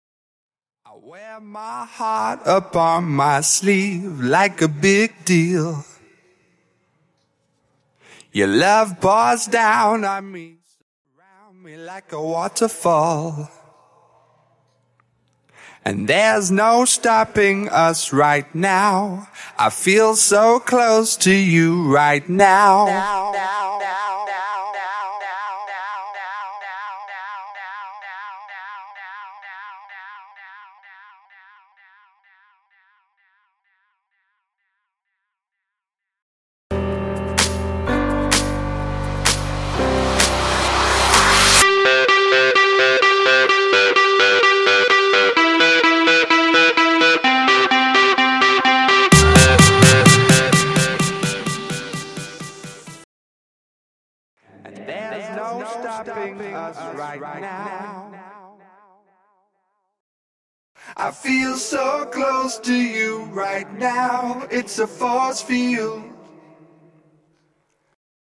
Studio Backing Vocals Stem
Studio Echo Vocal Stem
Studio Instrumental